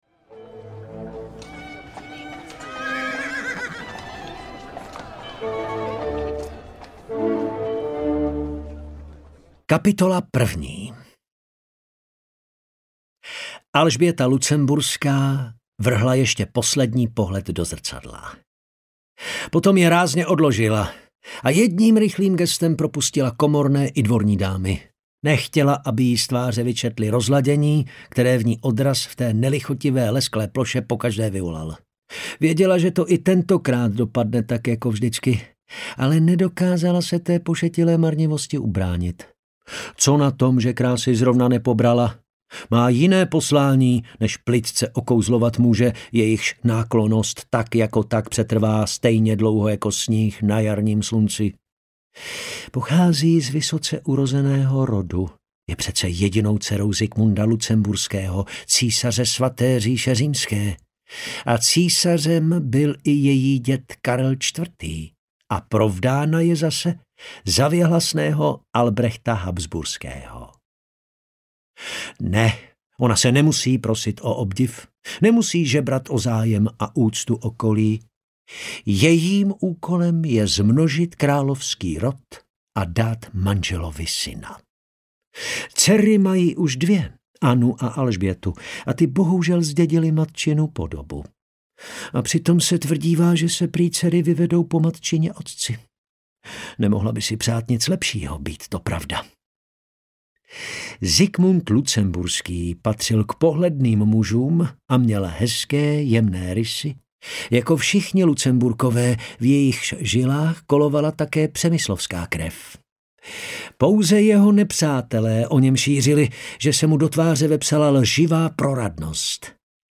Audio knihaPoslední Lucemburk
Ukázka z knihy